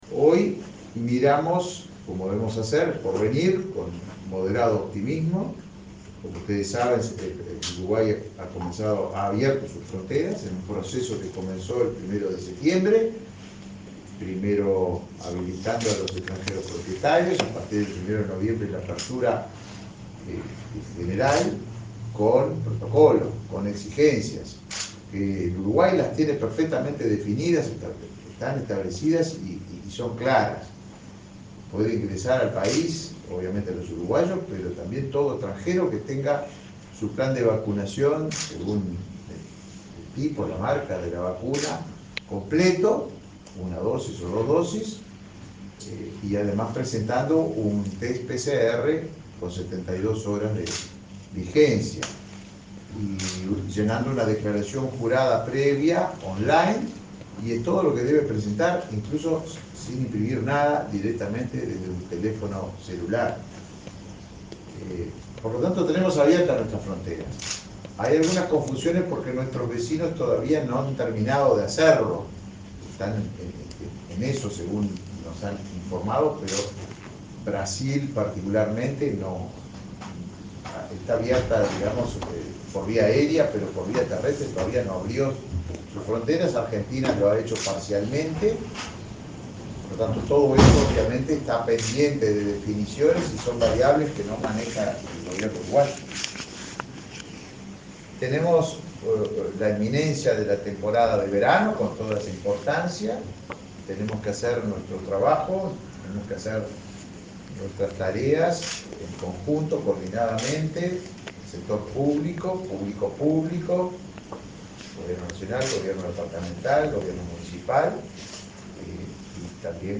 Conferencia del ministro de Turismo, Tabaré Viera
Conferencia del ministro de Turismo, Tabaré Viera 12/11/2021 Compartir Facebook X Copiar enlace WhatsApp LinkedIn El ministro de Turismo, Tabaré Viera, se reunió este viernes 12 con el intendente de Rocha, Alejo Umpiérrez, en el Fortín de San Miguel, y, luego, brindó una conferencia de prensa.